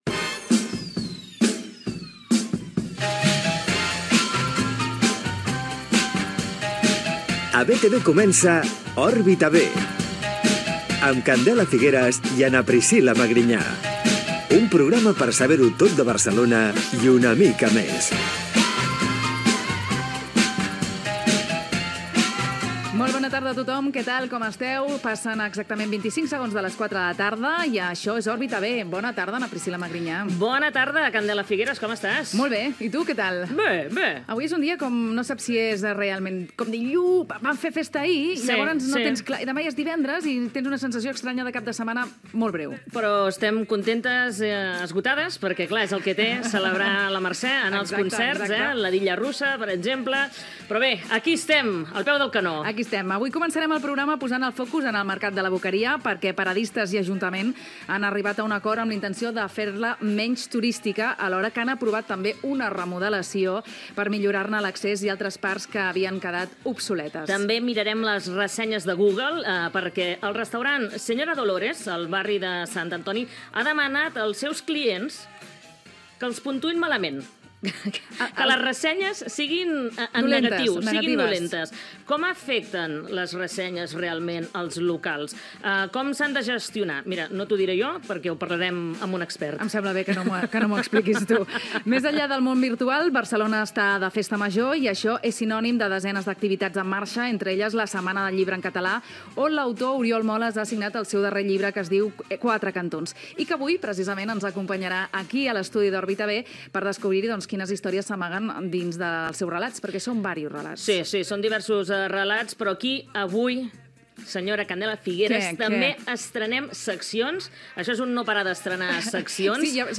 Careta del programa, diàleg inicial, sumari, comentaris sobre l'Arc de Triomf de Barcelona, equip, formes de contactar amb el programa
Entreteniment
FM